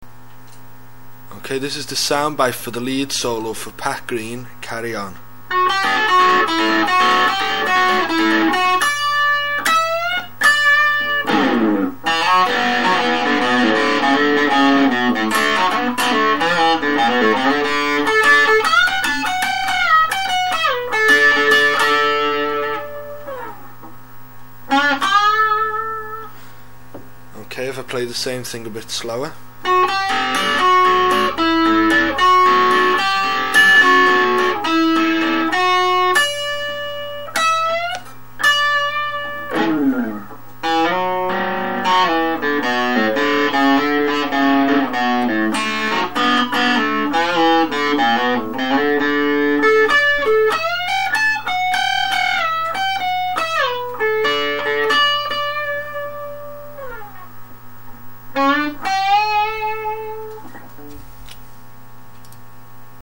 w/heavy distortion